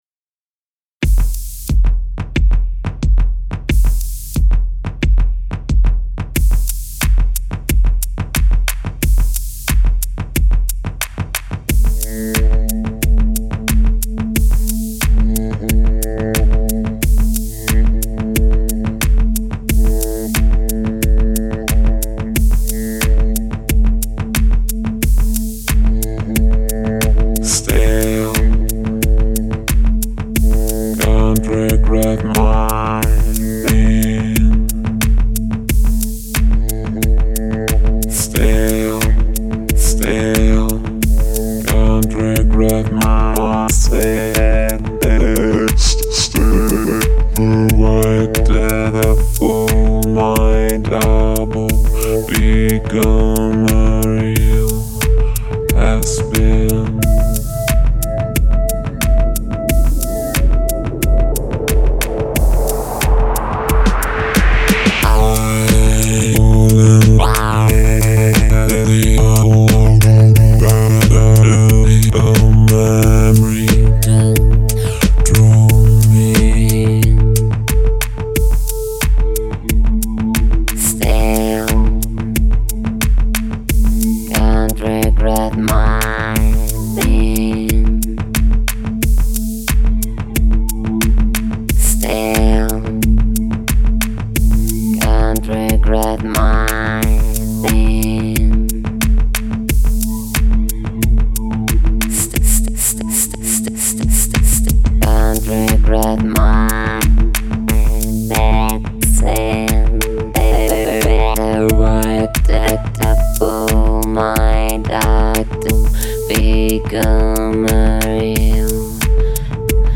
electro/synth territory